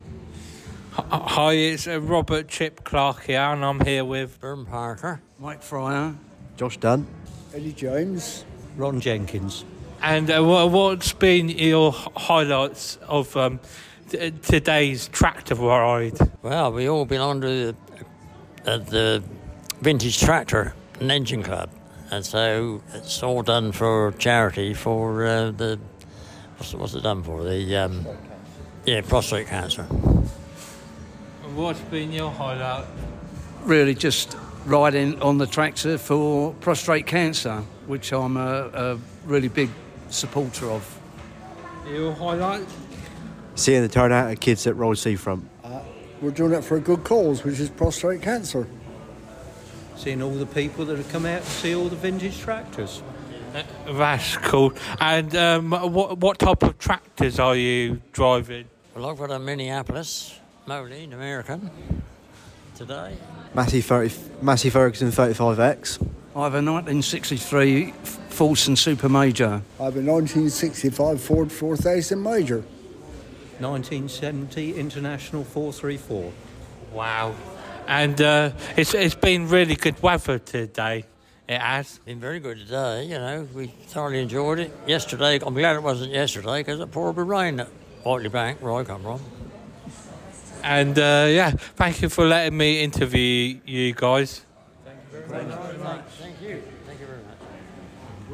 Tractor Ride out Interview 2024